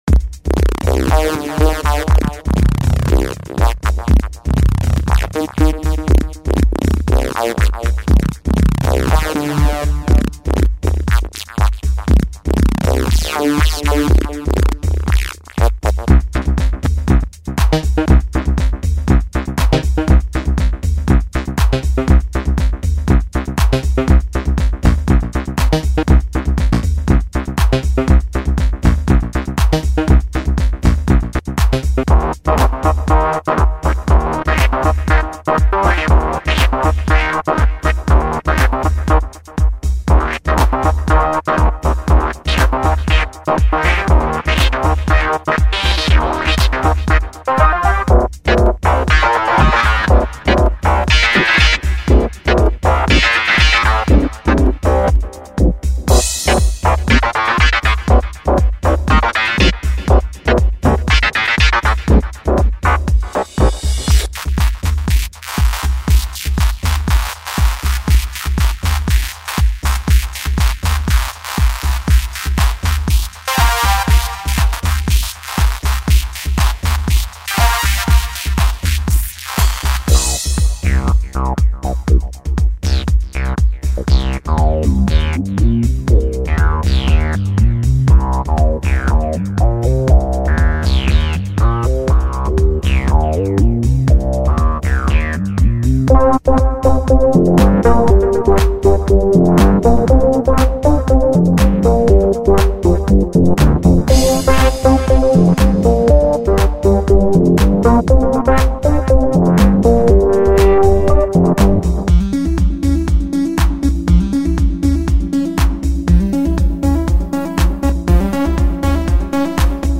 No external FX were used. A PIII 1 Ghz PC with Logic Audio V5.5 was used .No mastering was done.
No external FX used - Custom Drumloop
blue_tronics_bank_demo.mp3